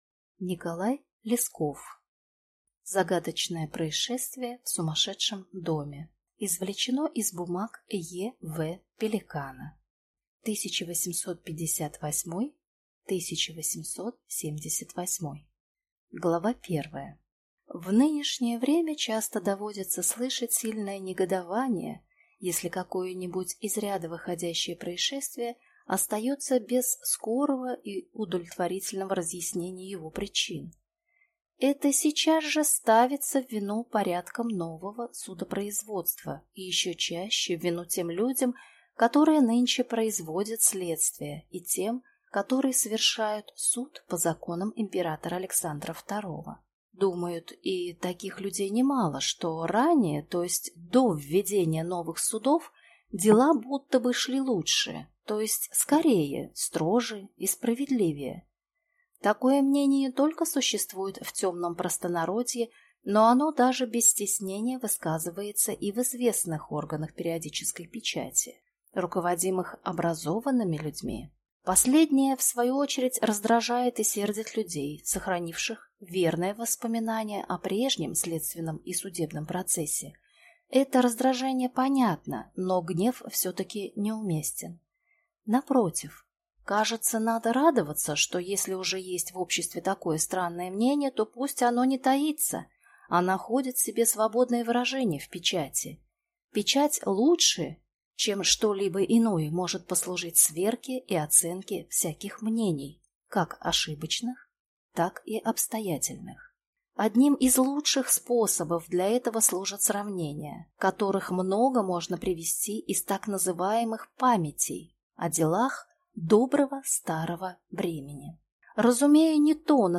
Аудиокнига Загадочное происшествие в сумасшедшем доме | Библиотека аудиокниг